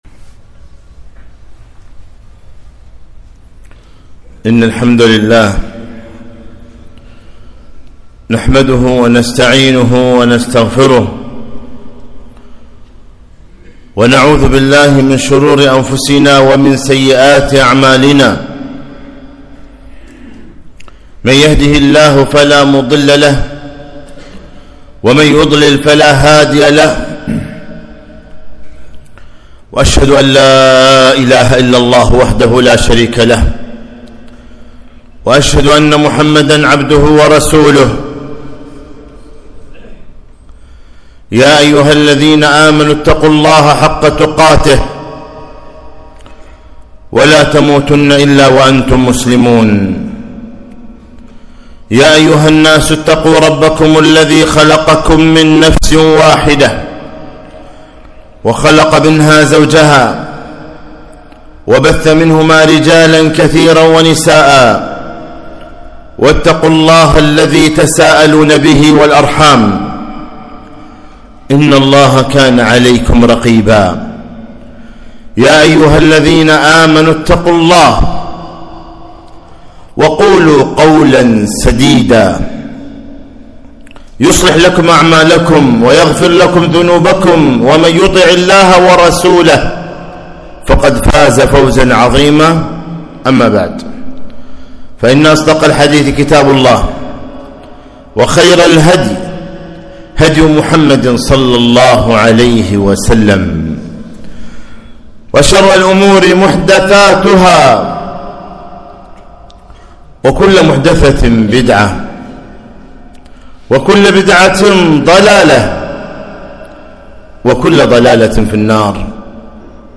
خطبة - اهتم بعقيدتك